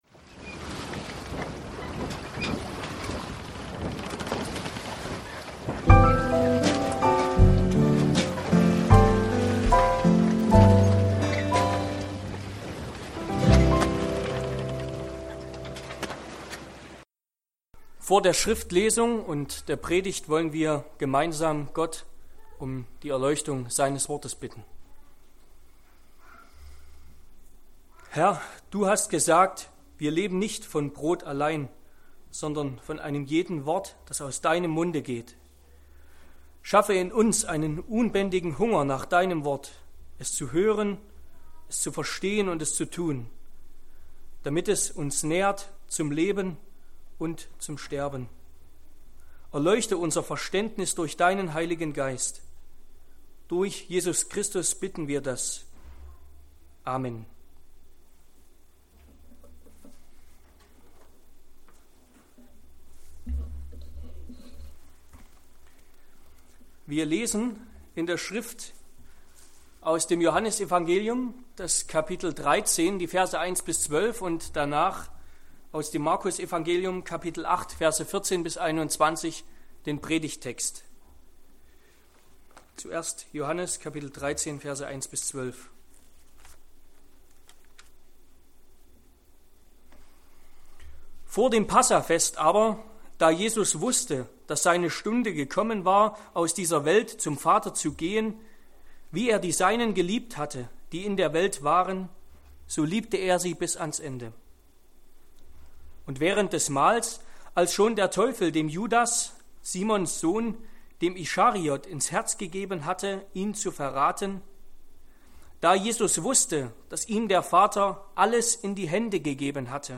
Über unausweichliches Scheitern und ein gutes Ende – Predigt zu Mk 8